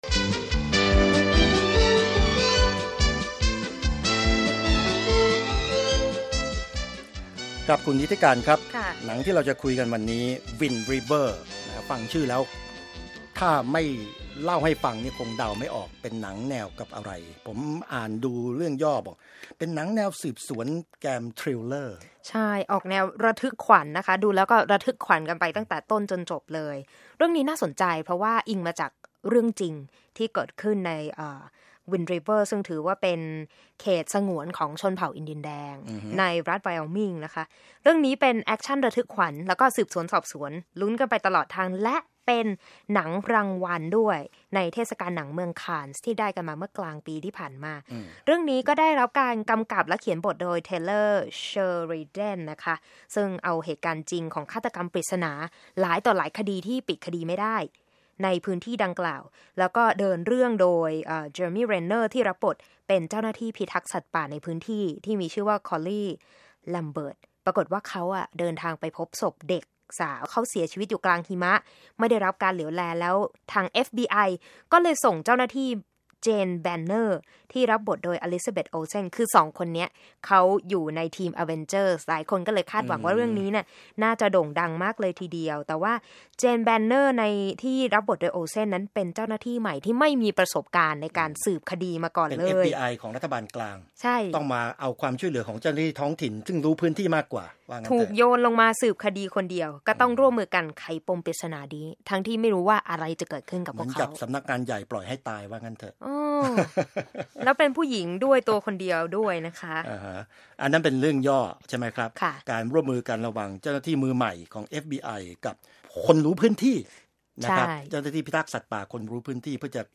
วิจารณ์ภาพยนตร์ Wind River